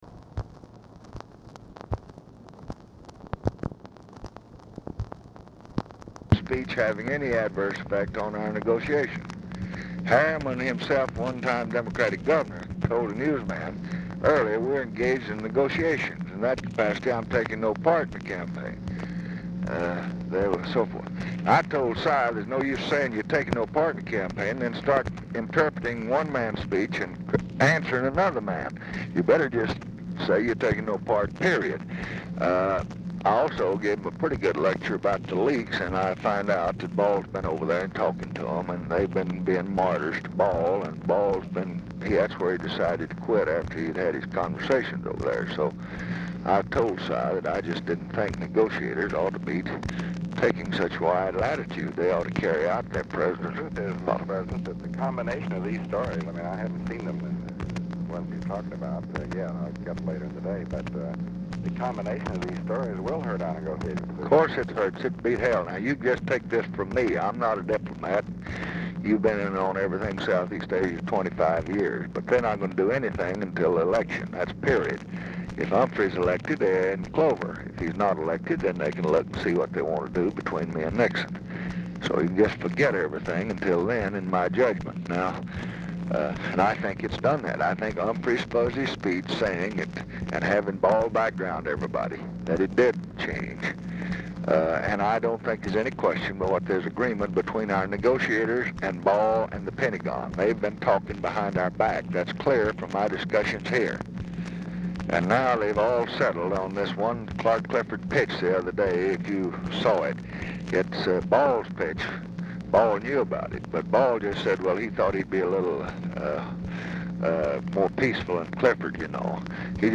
Telephone conversation # 13513, sound recording, LBJ and DEAN RUSK, 10/3/1968, 10:15AM | Discover LBJ
Format Dictation belt
Location Of Speaker 1 Mansion, White House, Washington, DC
Specific Item Type Telephone conversation